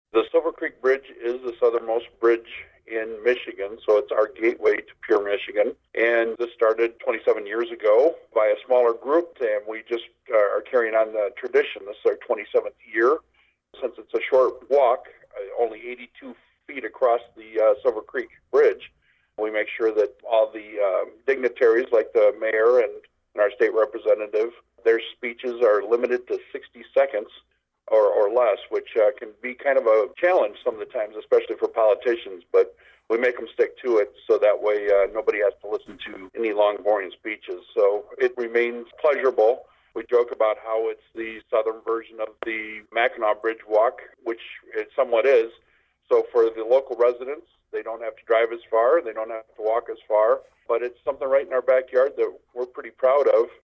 talked to WLEN News about the history behind the walk…